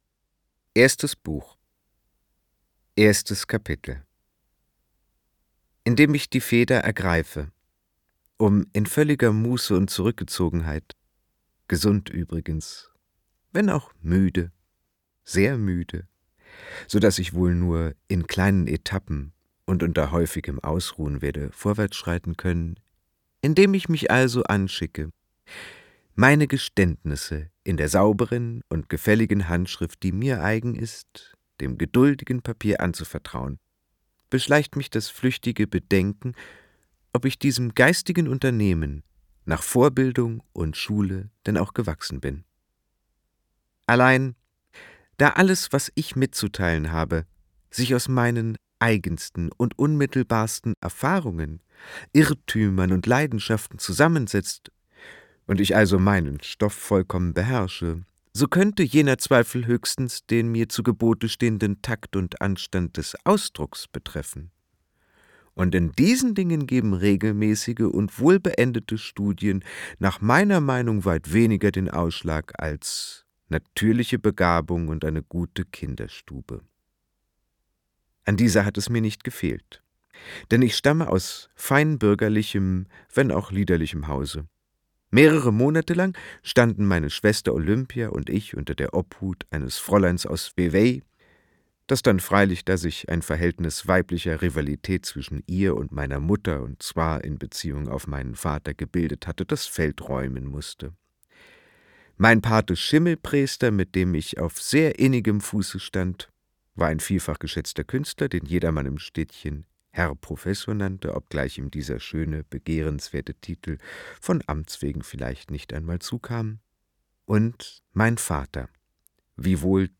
Bekenntnisse des Hochstaplers Felix Krull Gelesen von: Boris Aljinović
Boris AljinovićSprecher